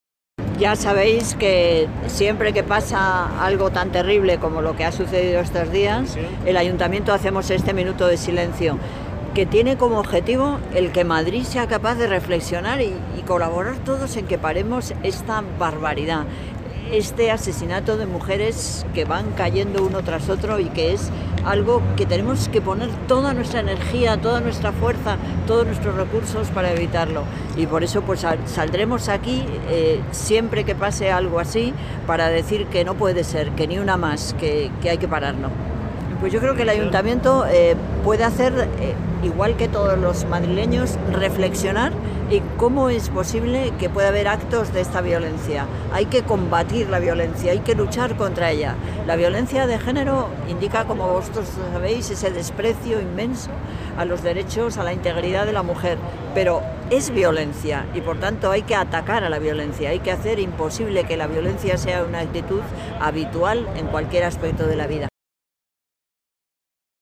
Nueva ventana:Declaraciones de Manuela Carmena tras el minuto de silencio